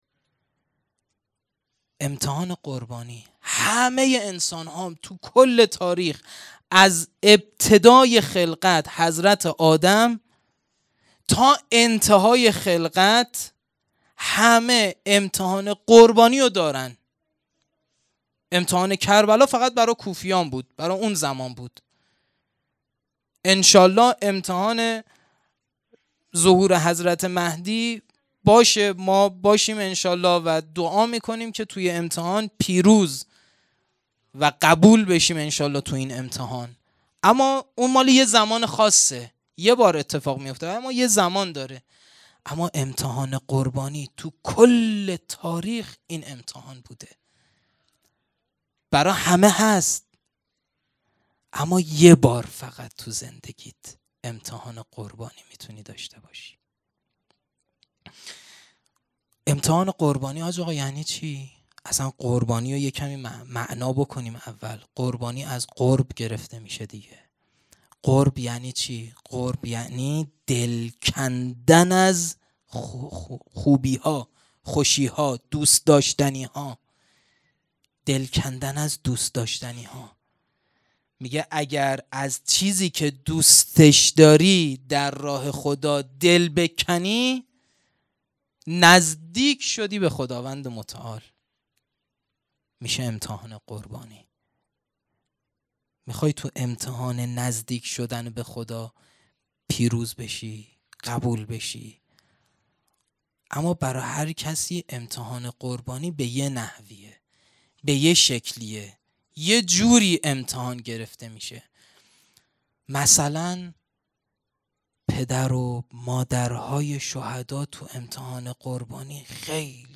سخنرانی
محرم 1440 _ شب دهم